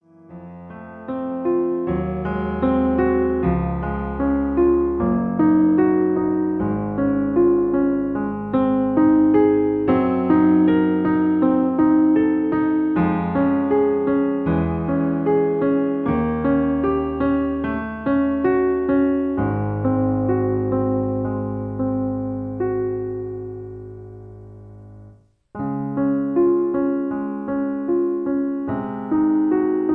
In D flat. Piano Accompaniment